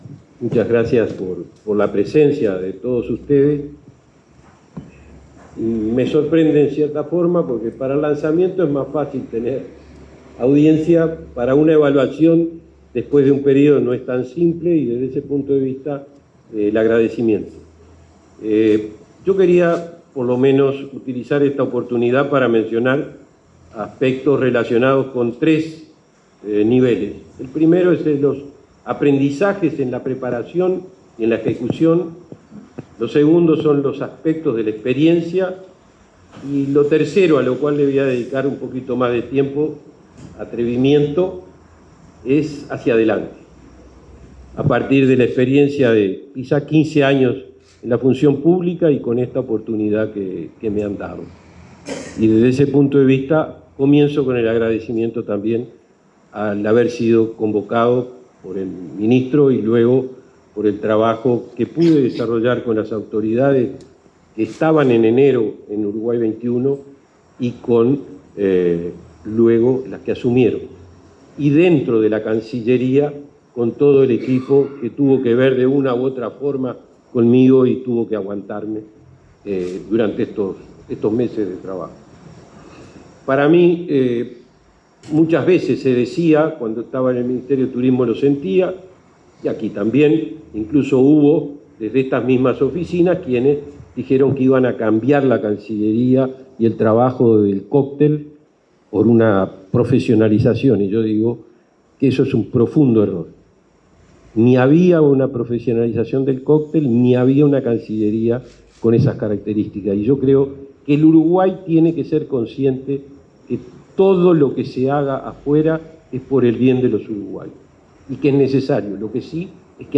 Palabras del comisario de Uruguay en la Expo Osaka, Benjamín Liberoff